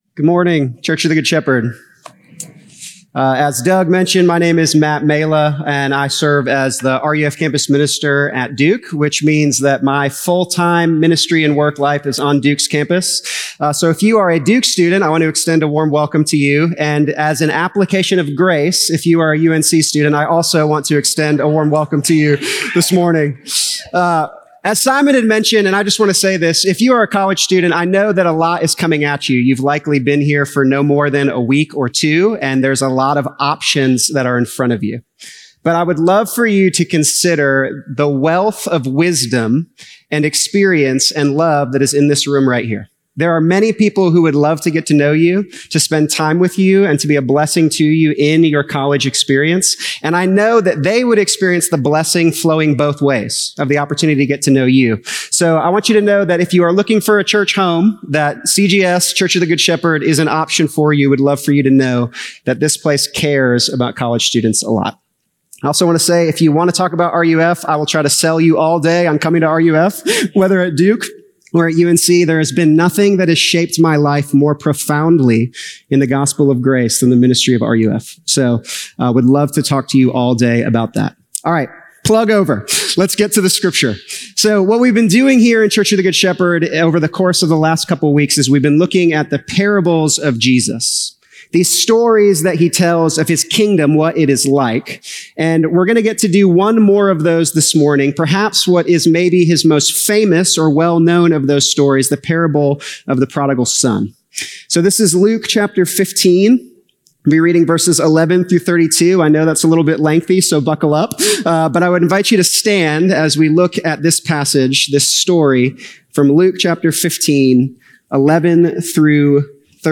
CGS-Service-8-24-25-Podcast.mp3